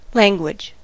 language: Wikimedia Commons US English Pronunciations
En-us-language.WAV